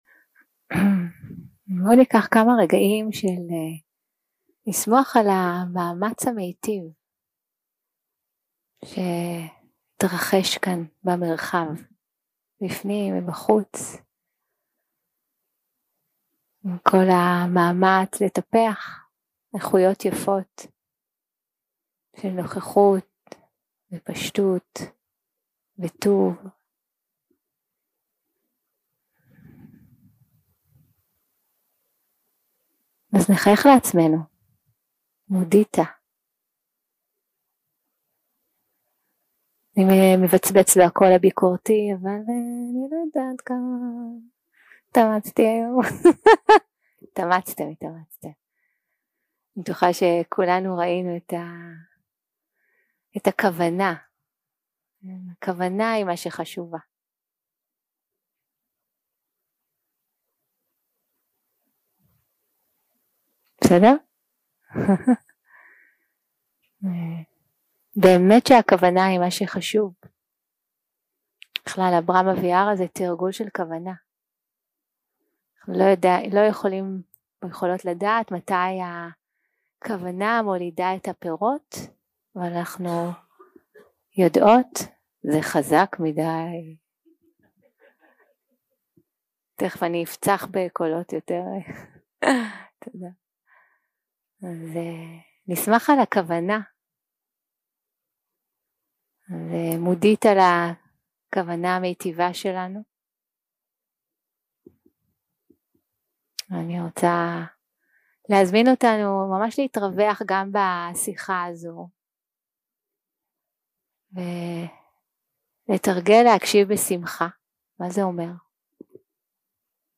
יום 2 – הקלטה 4 – ערב – שיחת דהארמה - ברהאמה ויארה - ארבע איכויות ללא גבול 0:00 0:00 סוג ההקלטה: Dharma type: Dharma Talks שפת ההקלטה: Dharma talk language: Hebrew